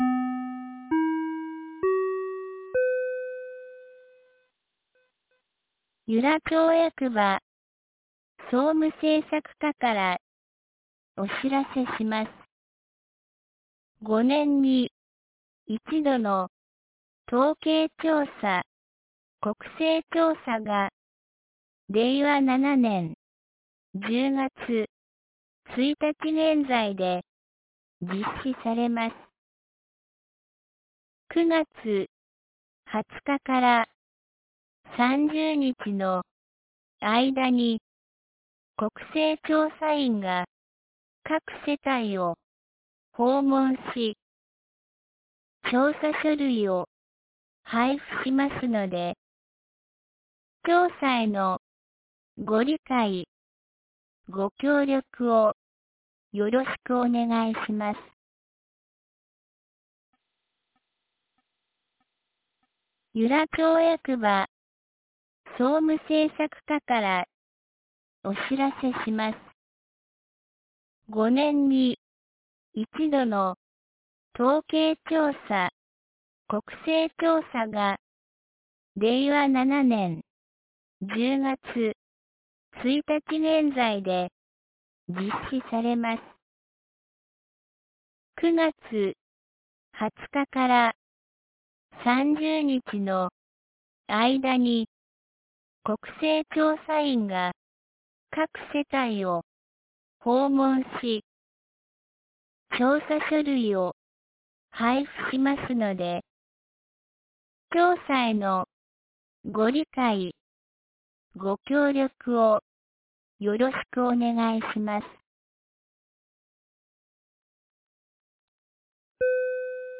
2025年09月21日 07時52分に、由良町から全地区へ放送がありました。